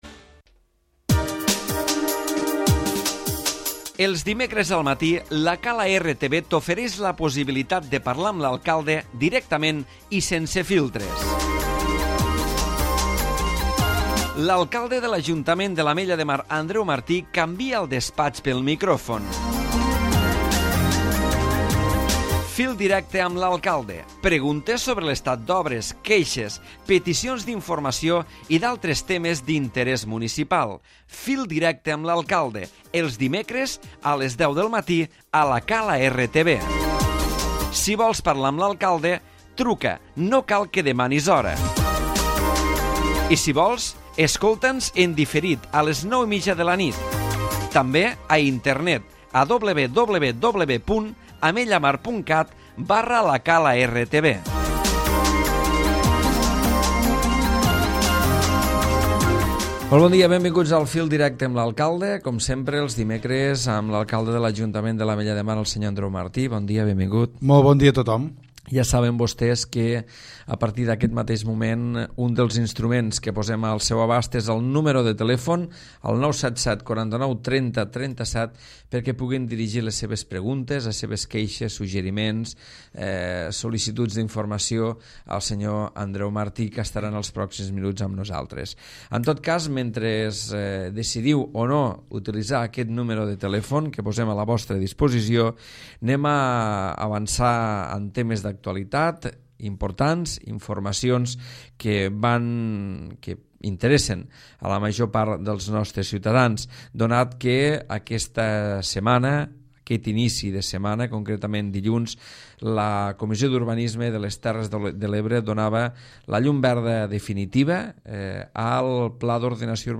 L'Alcalde de l'Ajuntament de l'Ametlla de Mar Andreu Martí ha basat la seva intervenció en el programa amb l'explicació dels beneficis que aportarà al municipi el nou Pla d'Ordenació Urbanística Municipal aprovat a primers de setmana, a la vegada que